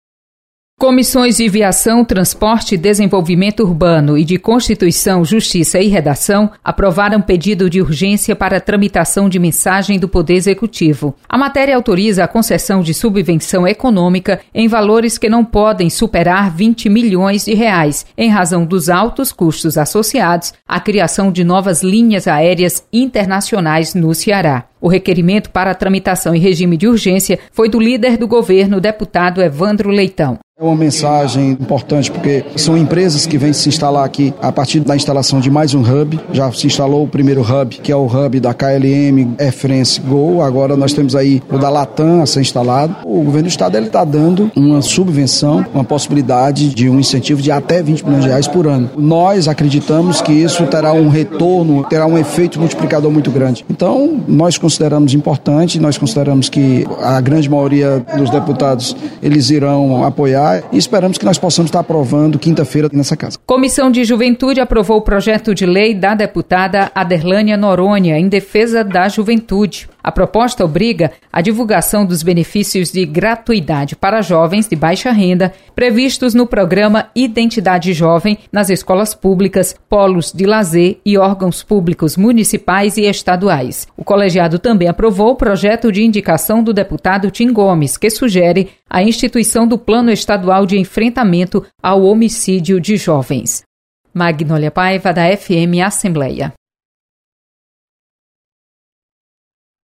Acompanhe resumo das comissões técnicas permanentes da Assembleia Legislativa com a repórter